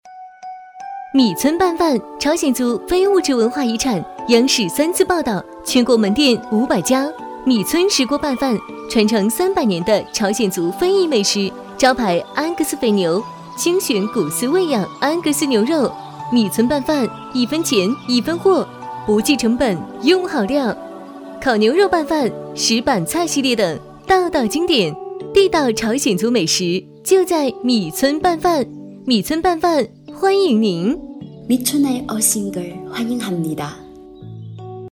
女3号